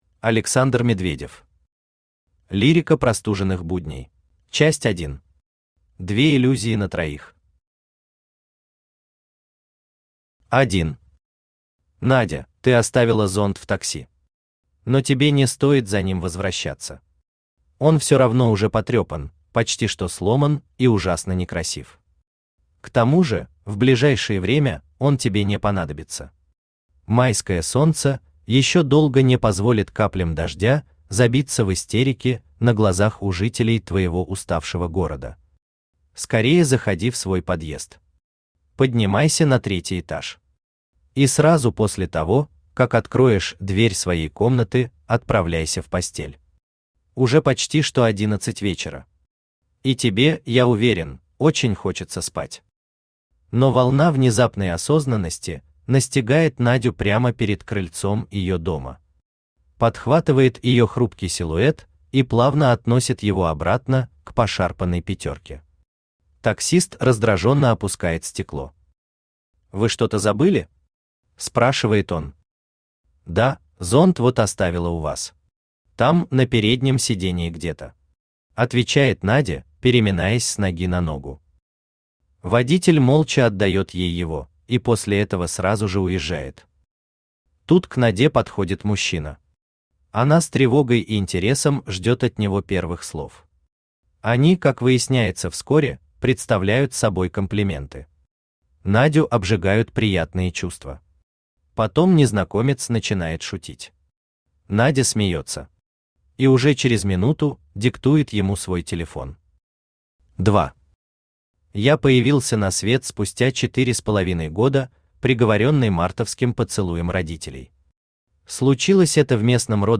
Аудиокнига Лирика Простуженных Будней | Библиотека аудиокниг
Aудиокнига Лирика Простуженных Будней Автор Александр Медведев Читает аудиокнигу Авточтец ЛитРес.